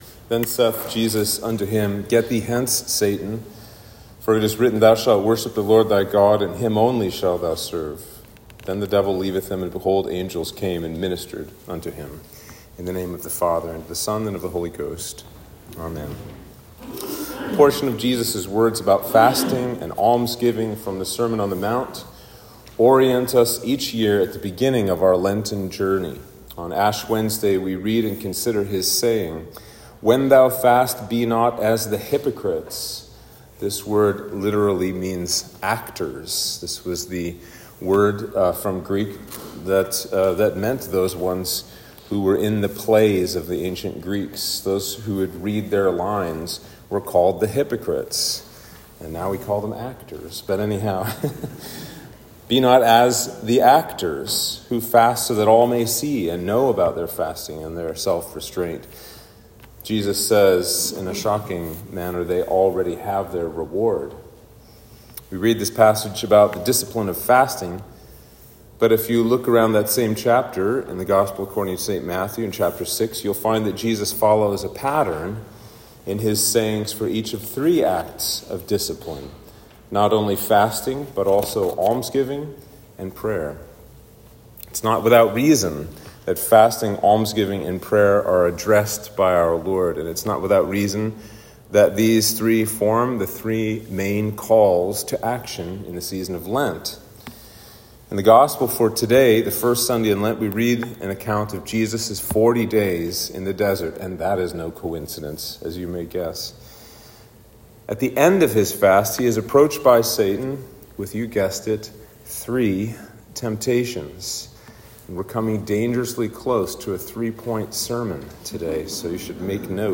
Sermon for Lent 1